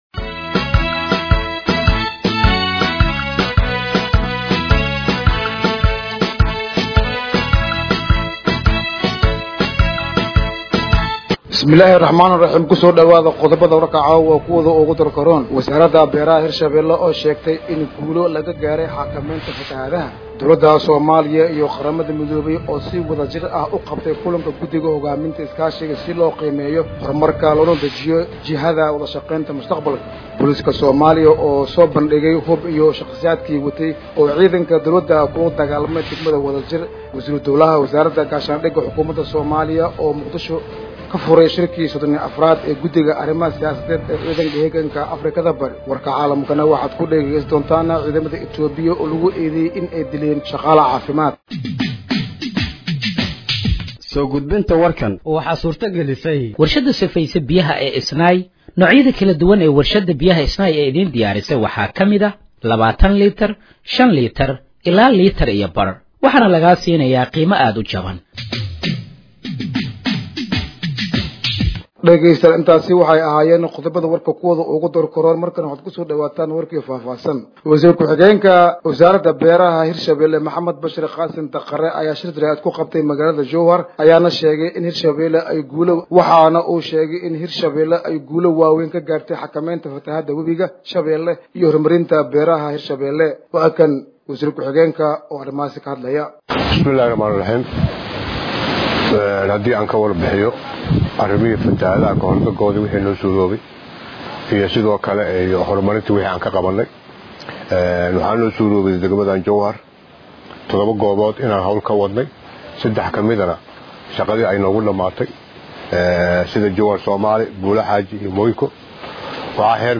Dhageeyso Warka Habeenimo ee Radiojowhar 15/07/2025